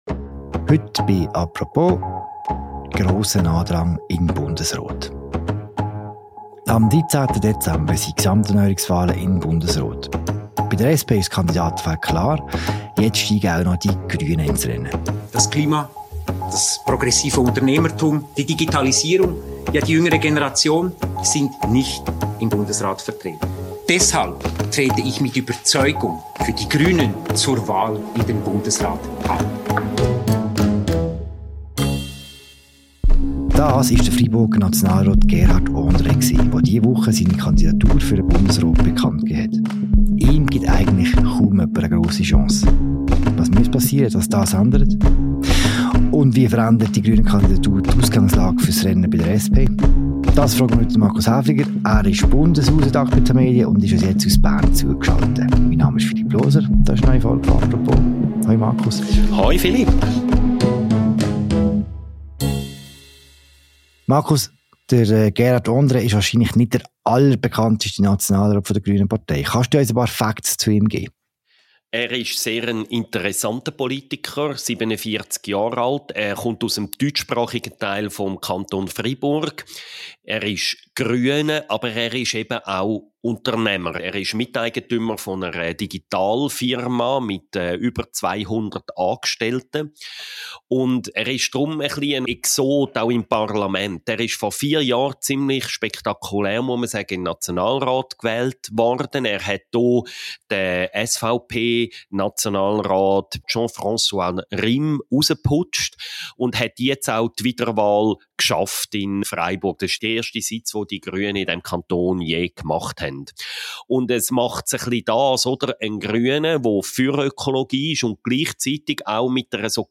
Er ist zu Gast bei Moderator